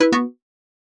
UI-Ok.ogg